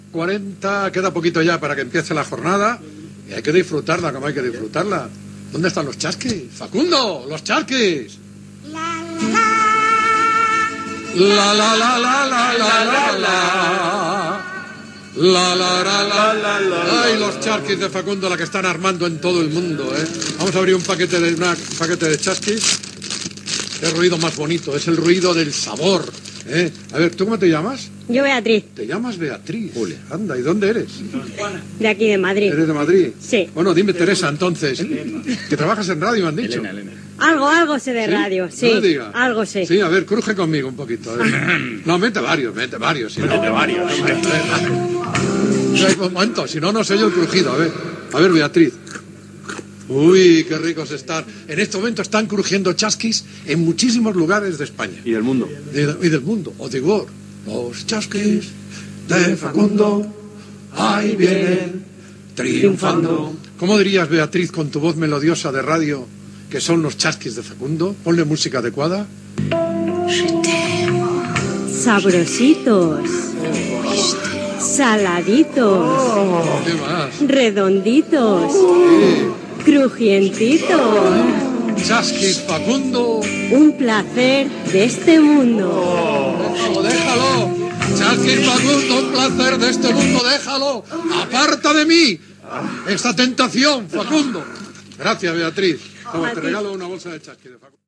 Publicitat de Facundo amb la intervenció d'una oïdora que està a l'estdui
Esportiu